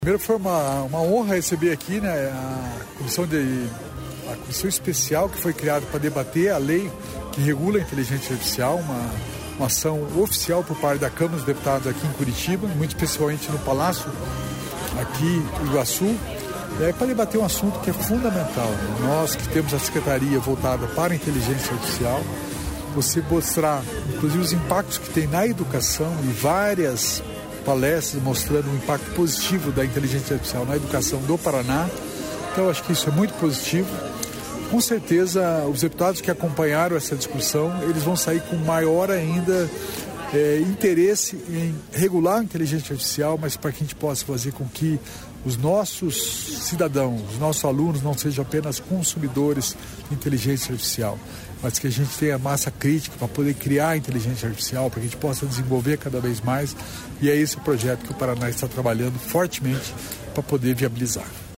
Sonora do secretário da Inovação e Inteligência Artificial, Alex Canziani, sobre o seminário em Curitiba de Inteligência Artificial na educação